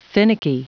Prononciation du mot finicky en anglais (fichier audio)
Prononciation du mot : finicky